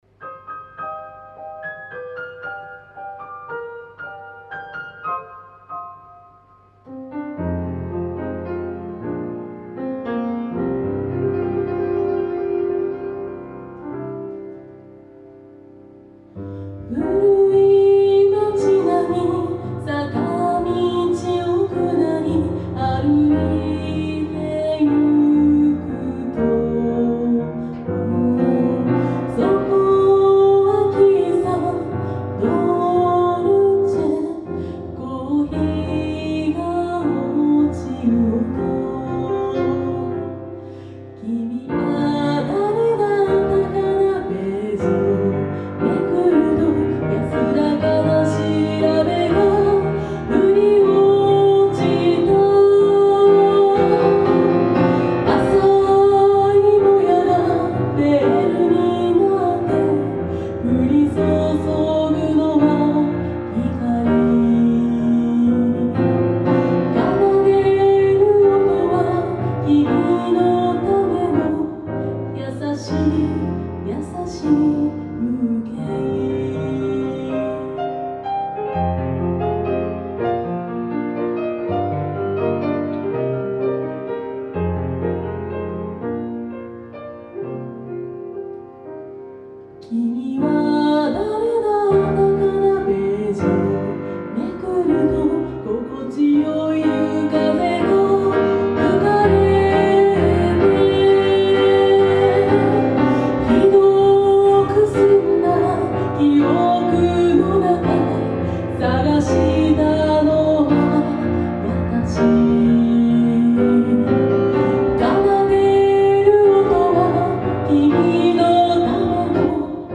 ピアノ
※１２年前のスタジオで録った音源ですので